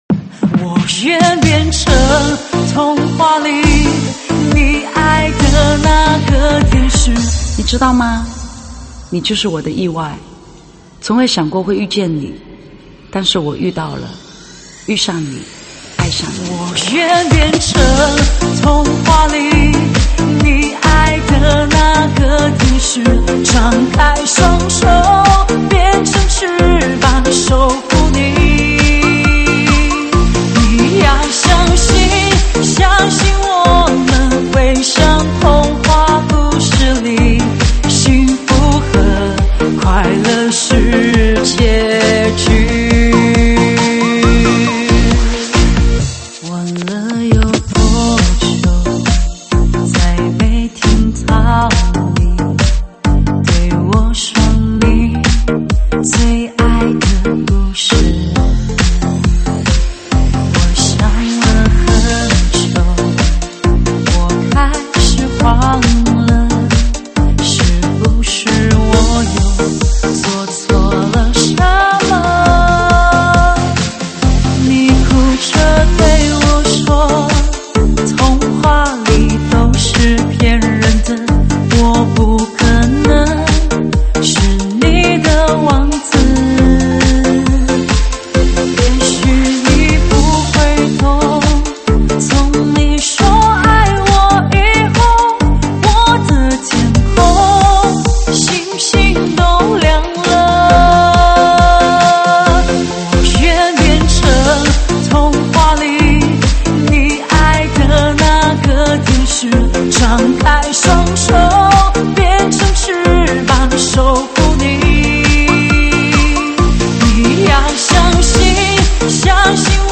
现场串烧
舞曲类别：现场串烧